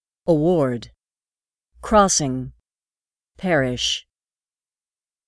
※今回からヒントとして単語の読み上げ音声を追加しました。